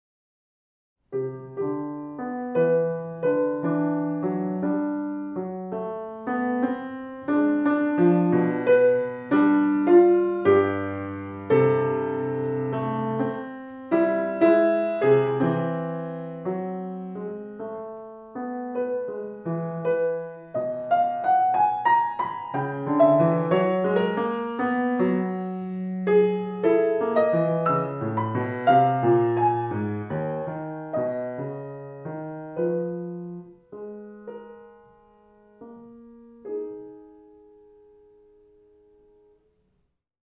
Musik zum Mantra 36 k — achtsam